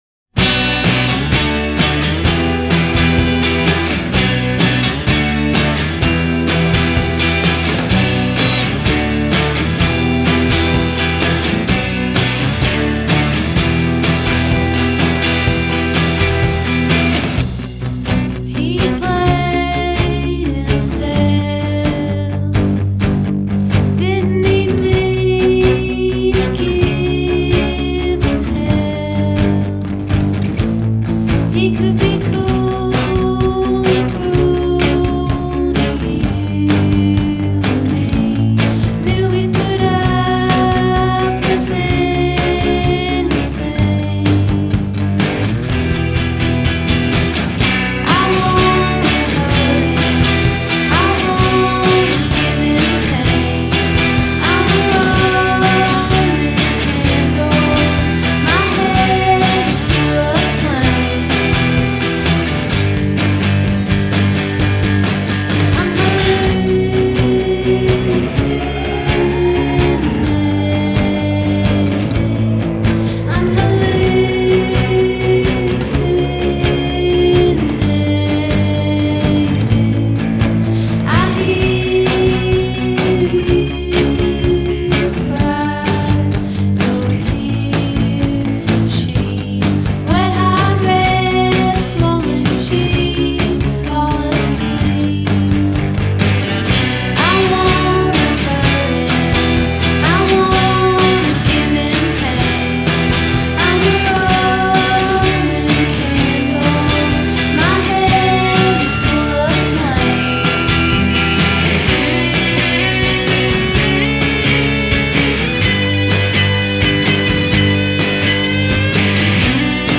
A cover